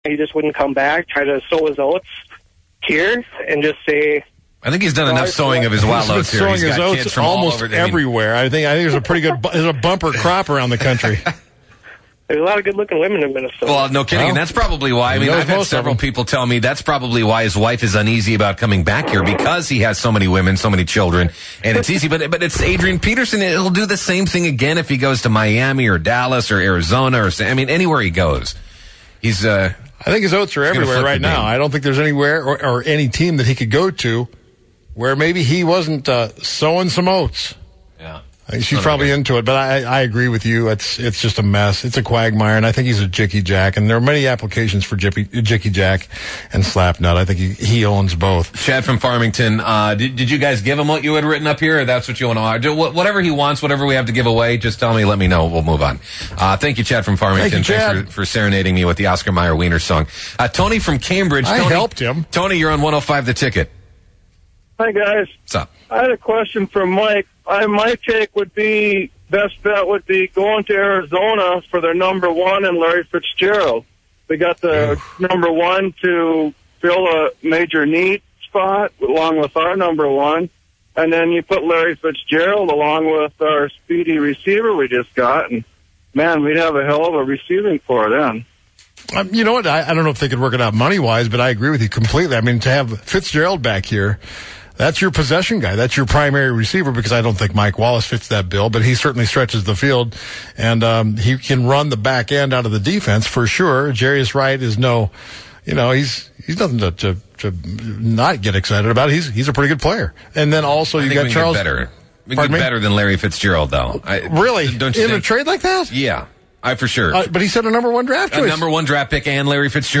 jumps on the phones to discuss a little Wild hockey.